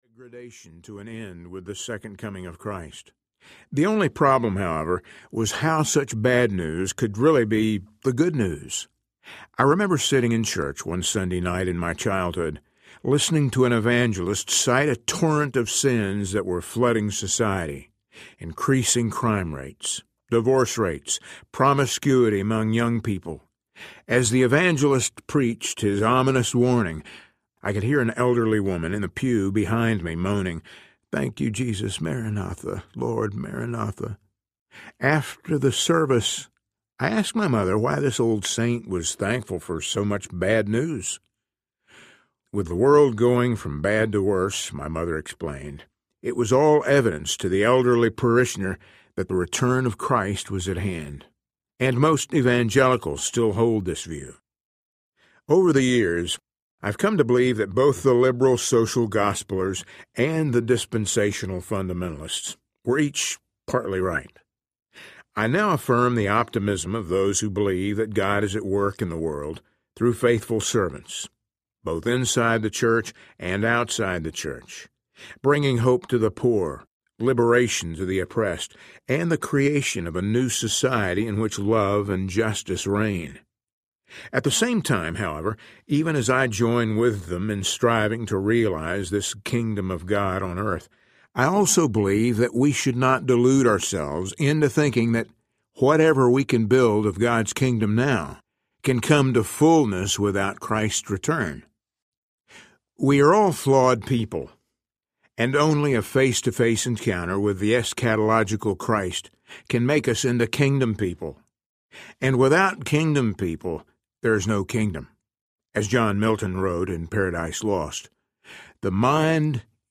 Adventures in Missing the Point Audiobook
7.9 Hrs. – Unabridged